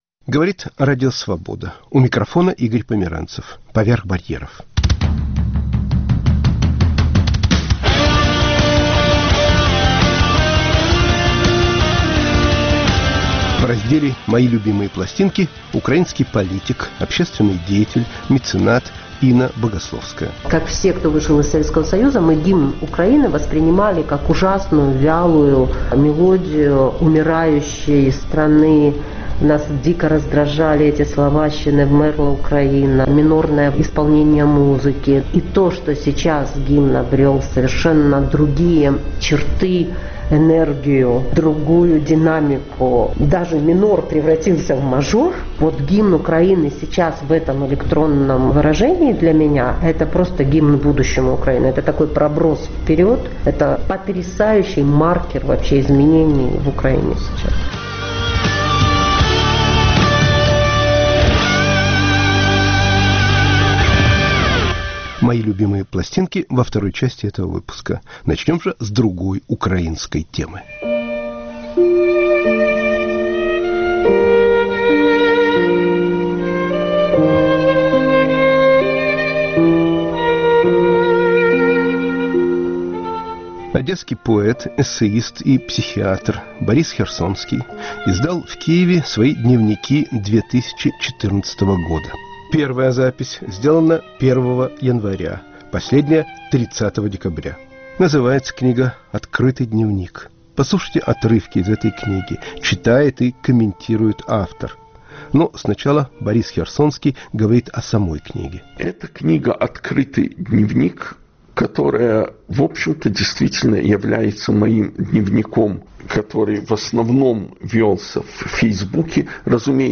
Поэт, эссеист, психиатр Борис Херсонский читает и комментирует отрывки из своего дневника 2014 года *** Переписка.